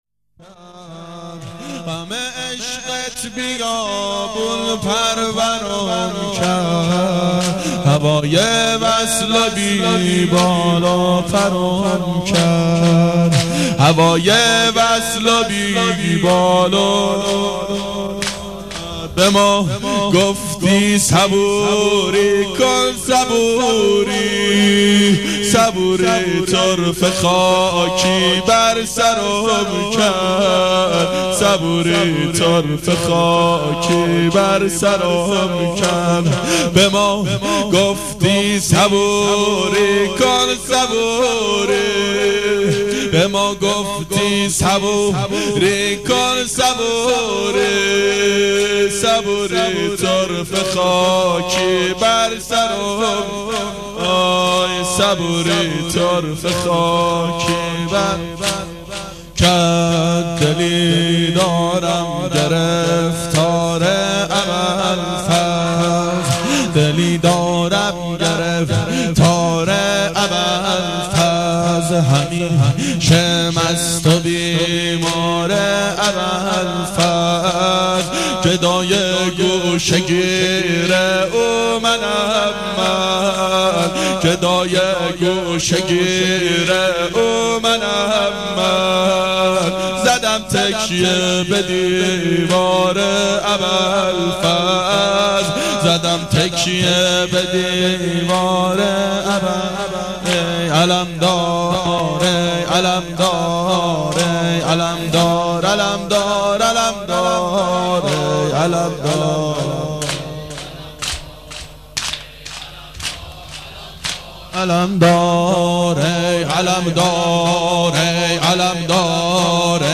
گلچین مداحی سال 1385